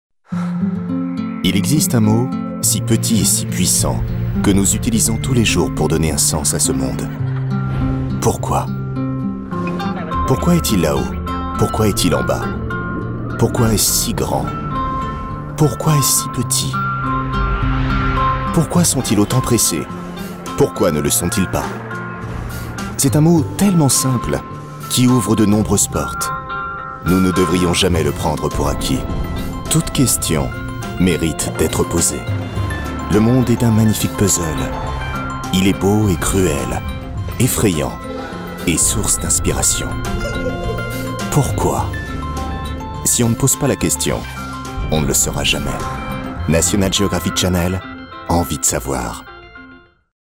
Voix-off spécialisée dans le ton pertinent et questionnant pour "Pourquoi ?"
Voix positive, posée et questionnante. Pour bande annonce générale des programmes National Geographic.
Avec une voix à la fois positive et questionnante, j’ai cherché à instiller une note de mystère, à susciter la curiosité des téléspectateurs.